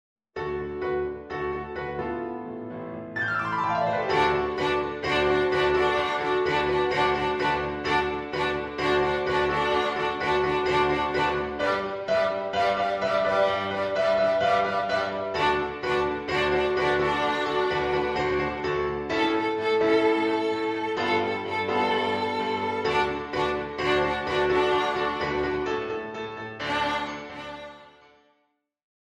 Ten easy ‘high-voltage’ pieces for Violin and Piano.
• Attractive original fun-based melodies.